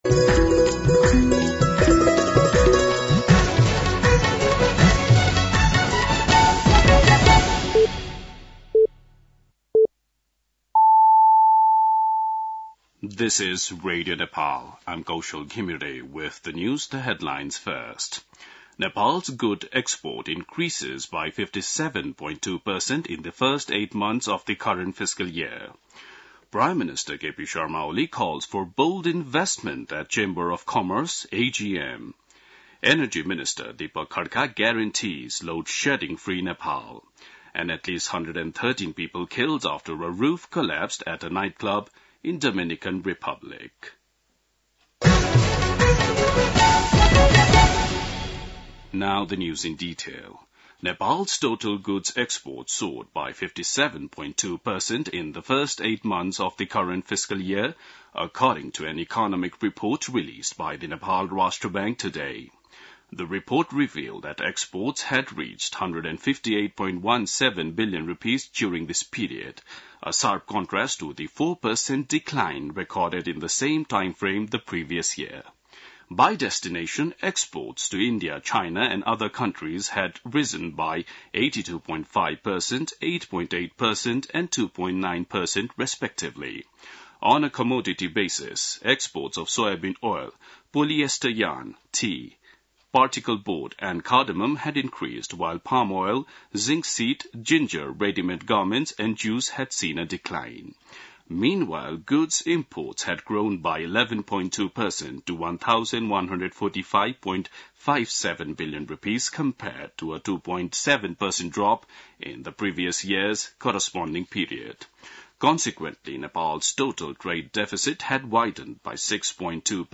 An online outlet of Nepal's national radio broadcaster
बेलुकी ८ बजेको अङ्ग्रेजी समाचार : २८ चैत , २०८१